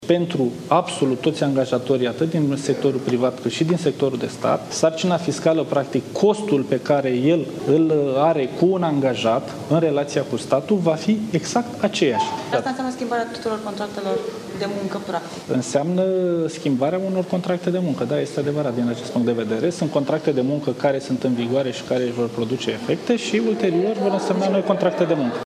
Declarația a fost făcută în Comisia pentru economie din Senat.